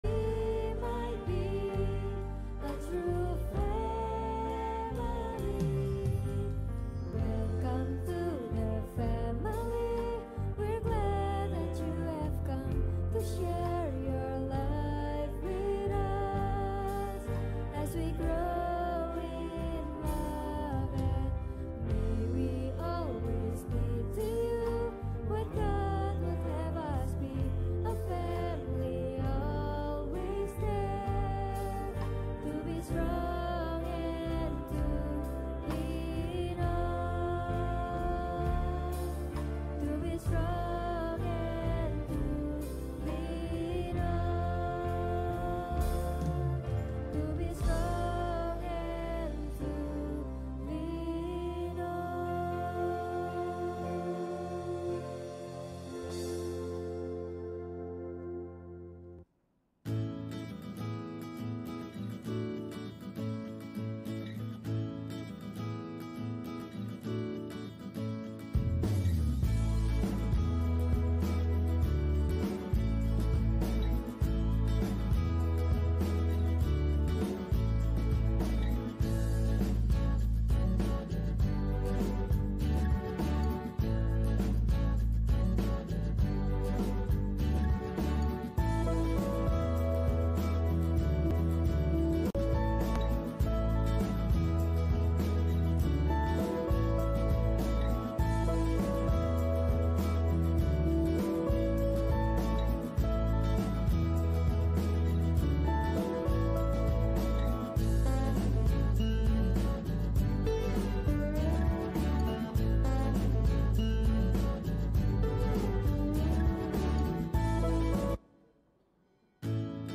Hormatilah Ayah Ibumu – Ibadah Minggu (Pagi 1)
hormatilah-ayah-ibumu-ibadah-minggu-pagi-1